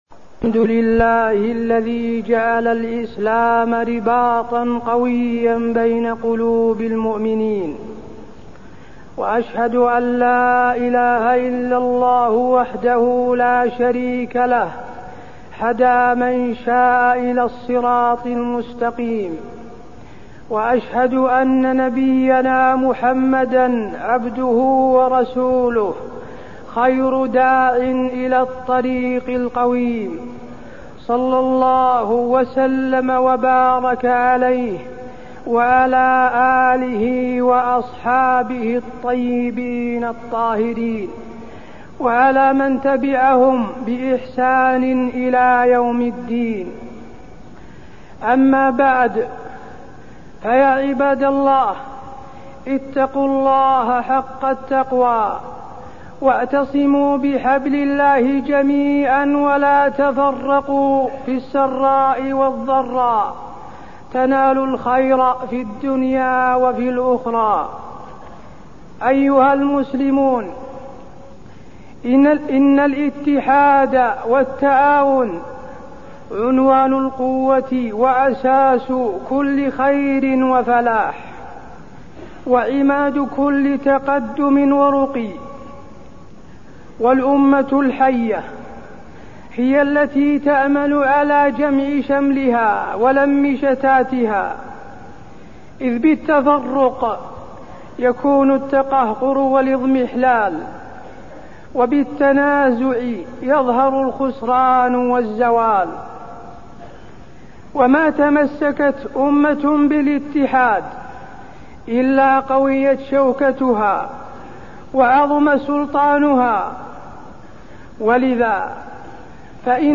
تاريخ النشر ١٩ محرم ١٤١٩ هـ المكان: المسجد النبوي الشيخ: فضيلة الشيخ د. حسين بن عبدالعزيز آل الشيخ فضيلة الشيخ د. حسين بن عبدالعزيز آل الشيخ الحث على تعاون المسلمين